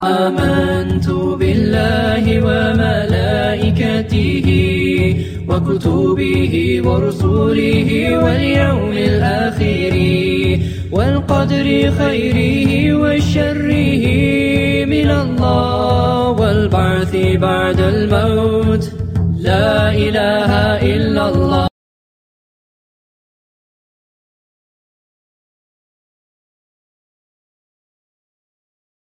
قم بتنزيل نغمات نشيد عربي اسلامي لهاتفك الخلوي.